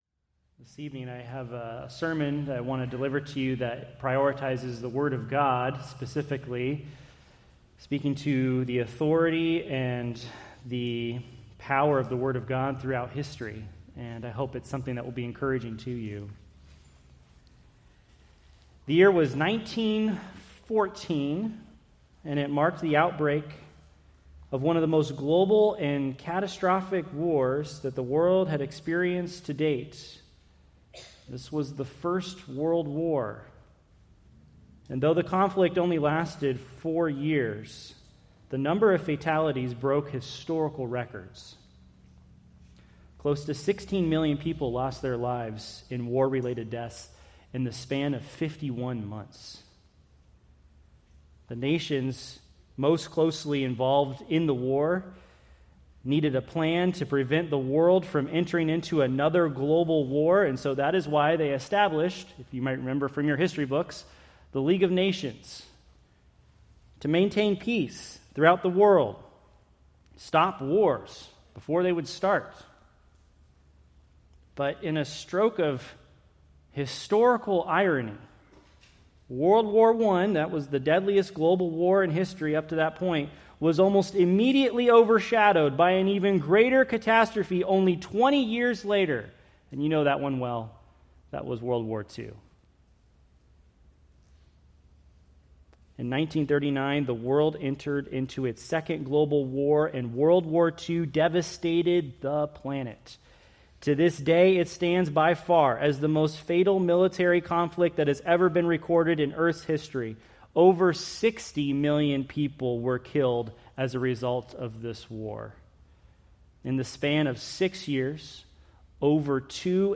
Preached July 20, 2025 from 1 Corinthians 14:20-25